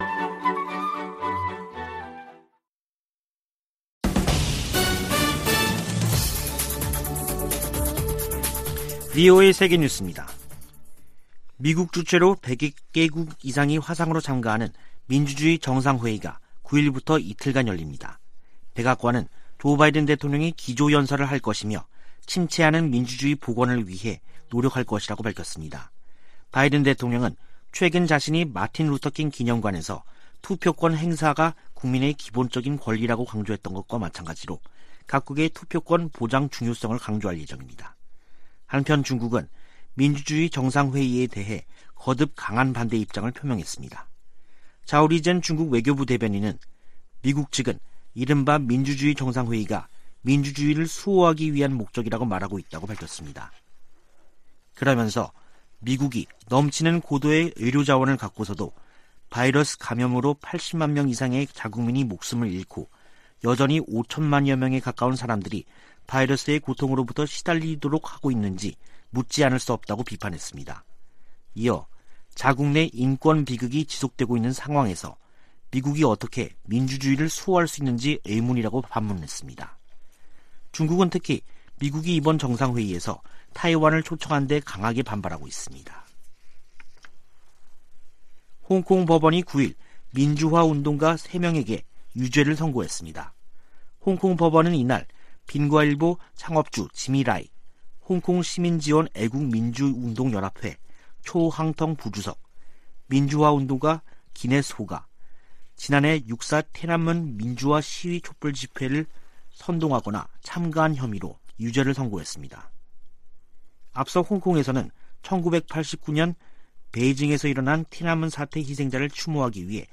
VOA 한국어 간판 뉴스 프로그램 '뉴스 투데이', 2021년 12월 9일 2부 방송입니다. 미국 공화당 하원의원 35명이 한국전쟁 종전선언을 반대하는 서한을 백악관에 보냈습니다. 한국 정부는 북한을 향해 종전선언 호응을 촉구하며 돌파구를 찾고 있지만 별다른 반응을 이끌어내지 못하고 있습니다. 미 상·하원 군사위가 합의한 2022회계연도 국방수권법안(NDAA) 최종안에는 '웜비어 법안' 등 한반도 안건이 대부분 제외됐습니다.